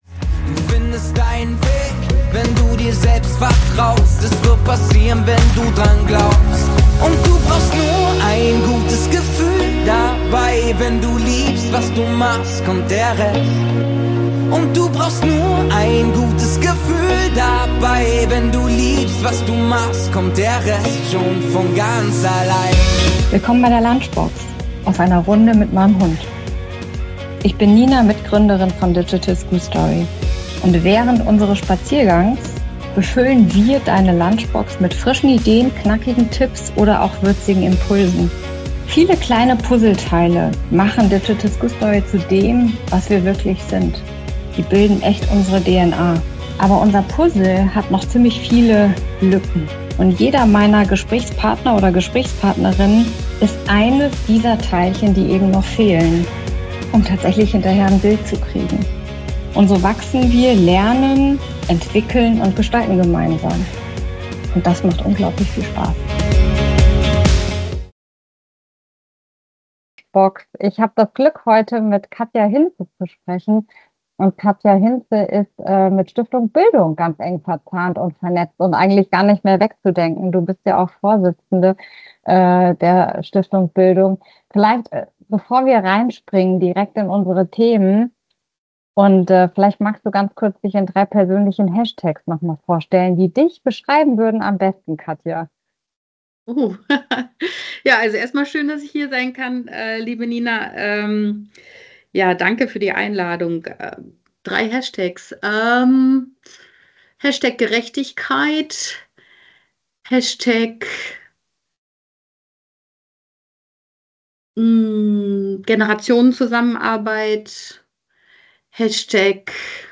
Dabei geht es nicht nur um Strukturen, sondern um echte Mitbestimmung, systemische Veränderungen und den Mut, Schule neu zu denken – ganz im Sinne einer Bildung, die vom Kind aus gedacht ist. Ein Gespräch voller Impulse, Visionen und ganz viel Herz für Engagement.